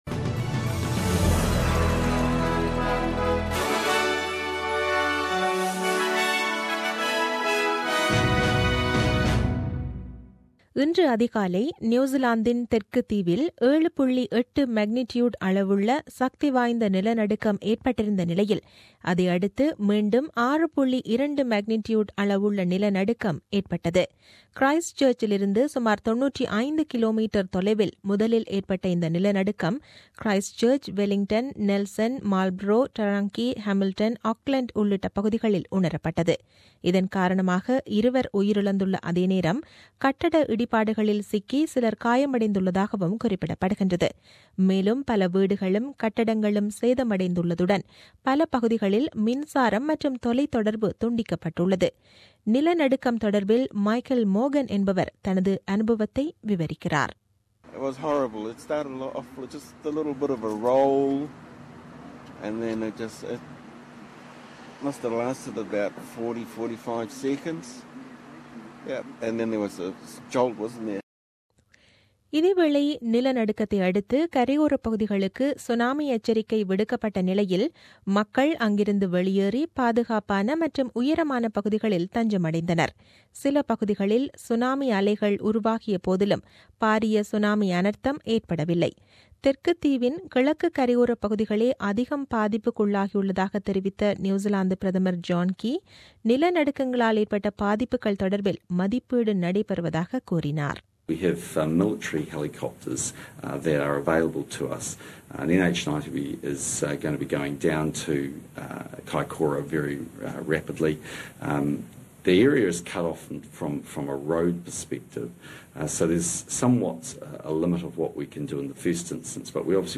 The news bulletin aired on 14 Nov 2016 at 8pm.